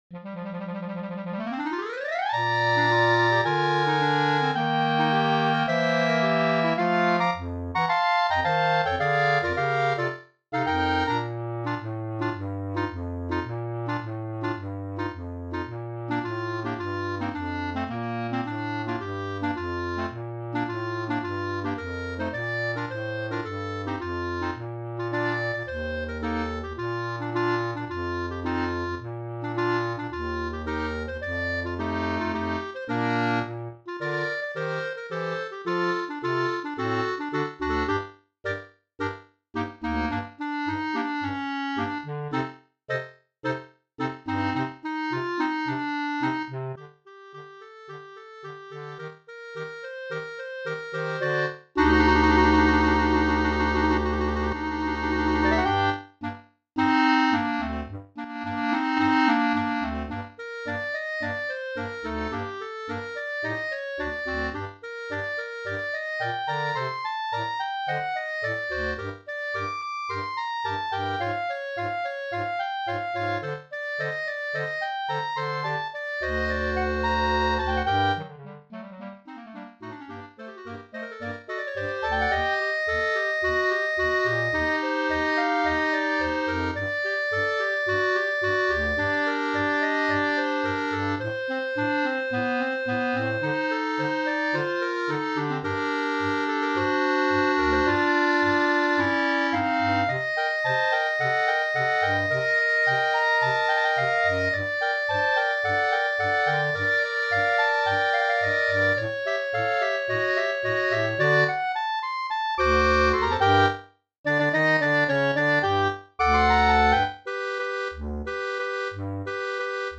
B♭ Clarinet 1 B♭ Clarinet 2 B♭ Clarinet 3 Bass Clarinet
单簧管四重奏
童谣